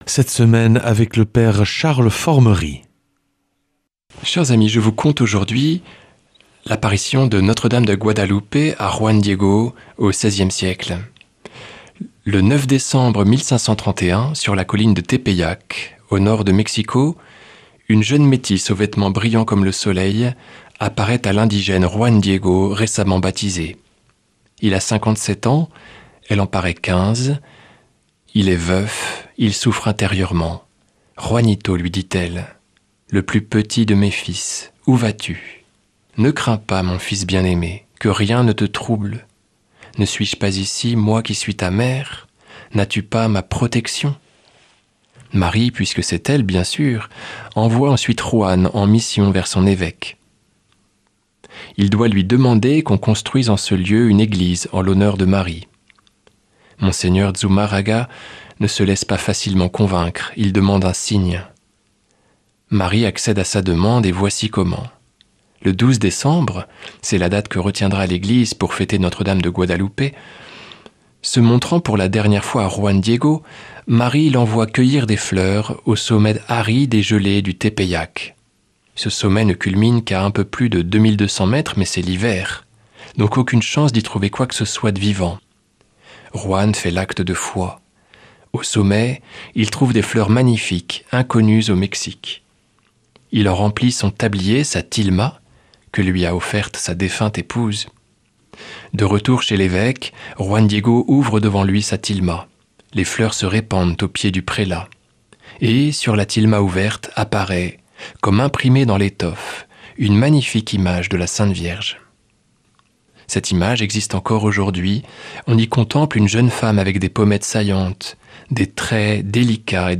mercredi 26 novembre 2025 Enseignement Marial Durée 10 min
Une émission présentée par